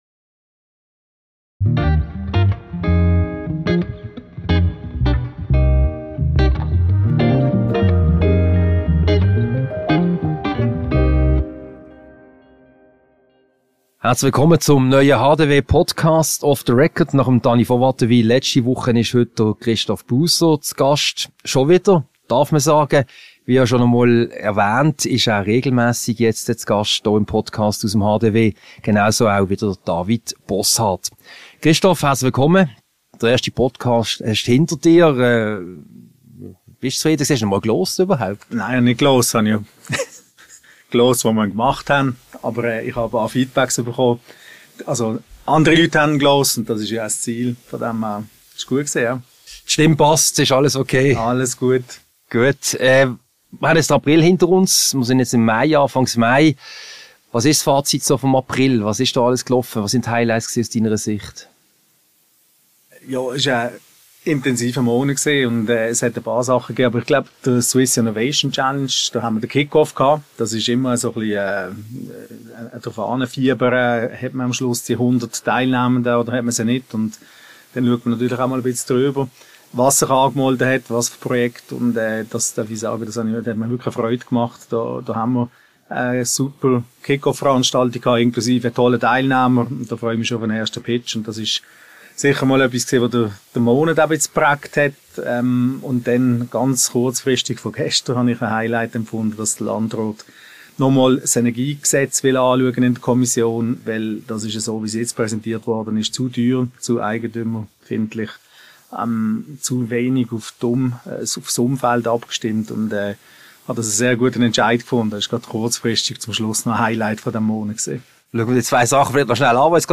Ein Gespräch über aktuelle Themen, die Vorbereitungen für die Berufsschau und den Tag der Wirtschaft sowie ein paar persönliche Fragen.